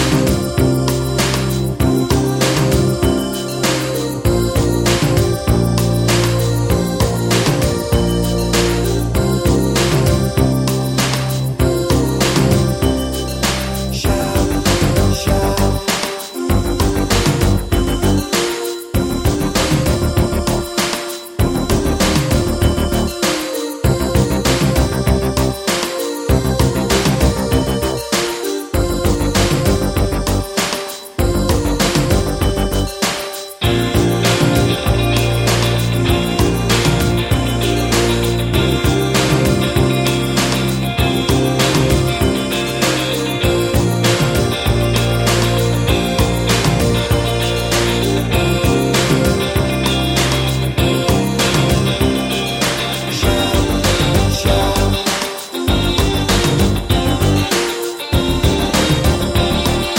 Radio Edit Pop (1980s) 4:15 Buy £1.50